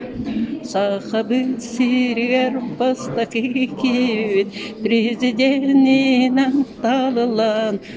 Собеседница исполнила отрывок упомянутой песни.